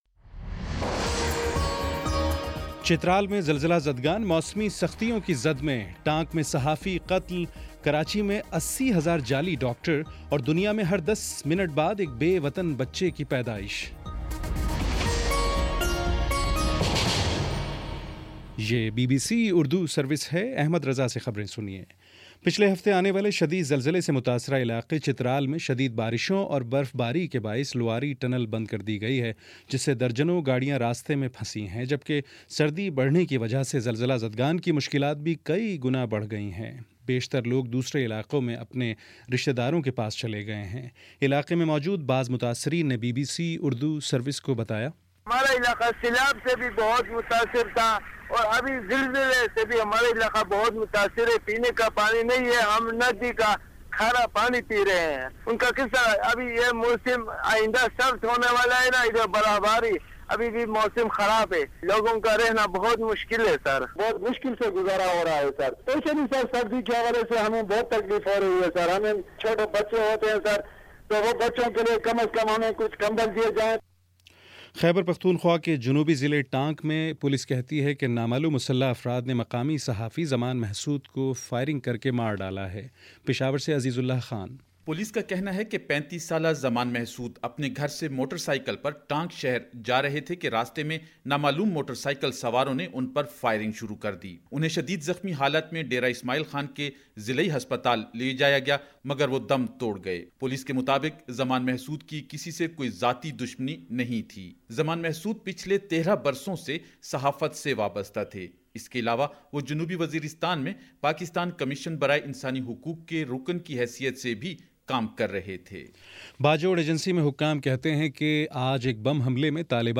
نومبر 03 : شام چھ بجے کا نیوز بُلیٹن